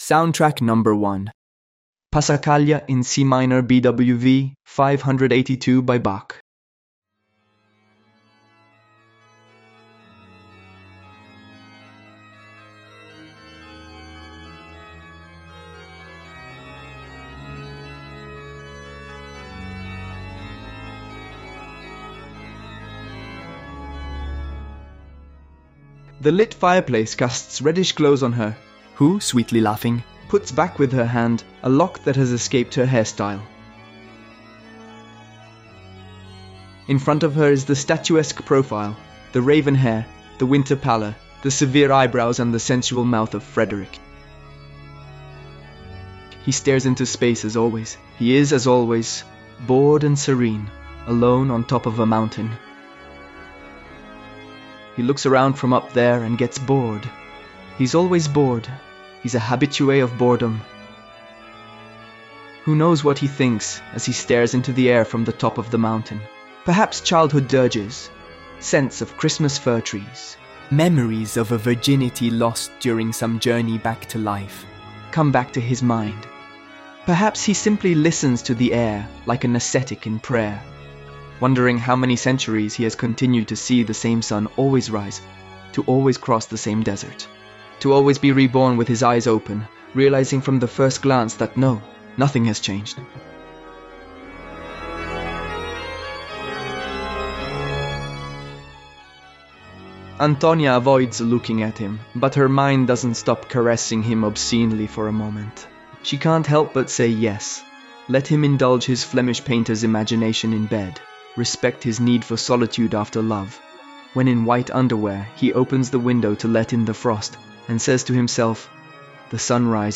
The soundtrack is "Passacaglia in C minor" by J.S. Bach.